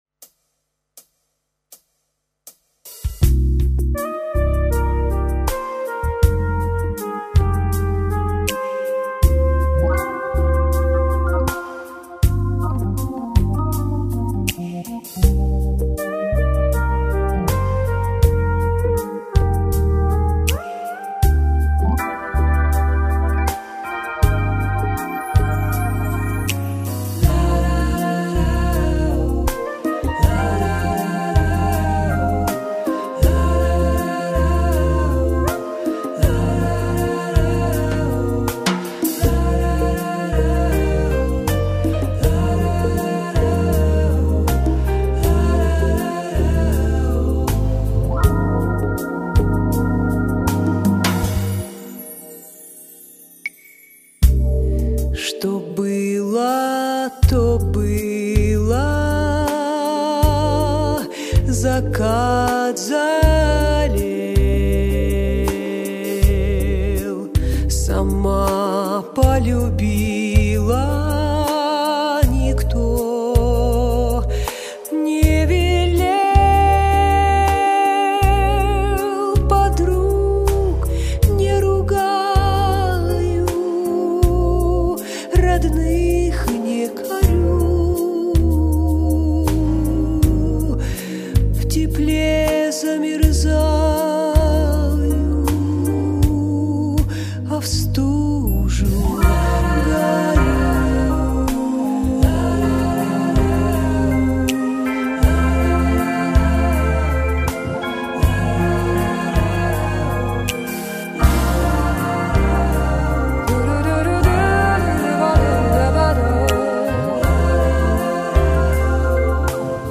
Аранжировка и вокал